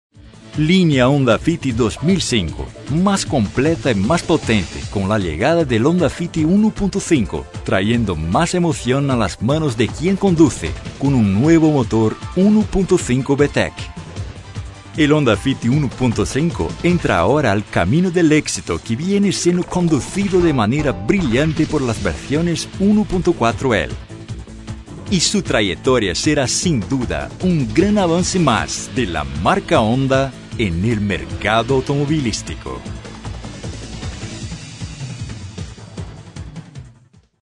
Locução em espanhol para vídeo do Honda Fit.